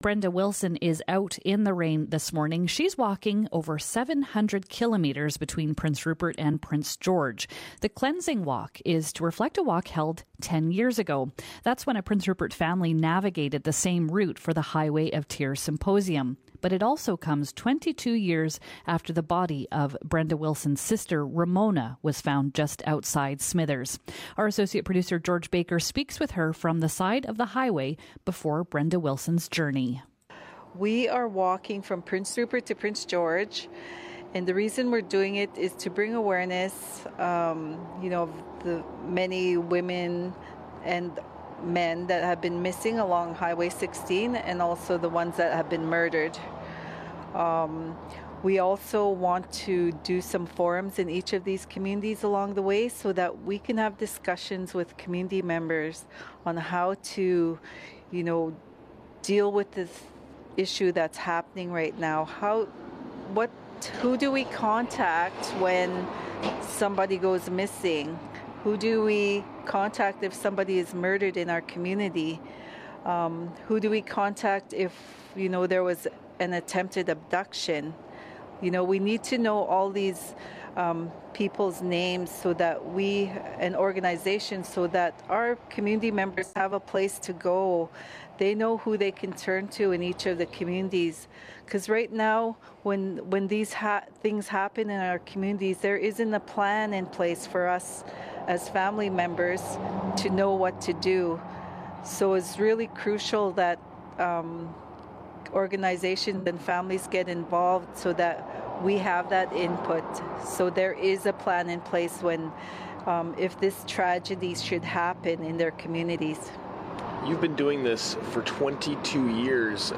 speaks with her on the side of the road